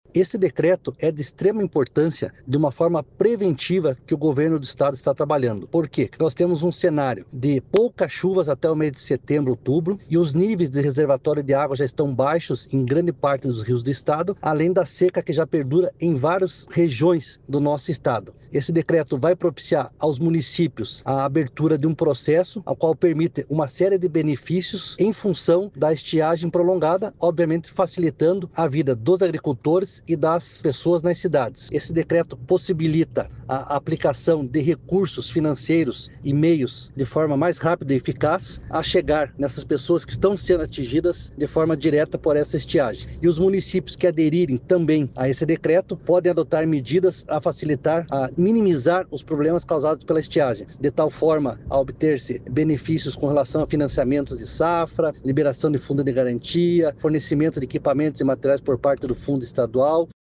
O decreto facilita a compra e contratação de serviços para o enfrentamento da estiagem e também o repasse de recursos. É o que explica  o coordenador estadual da Defesa Civil, coronel Fernando Schünig: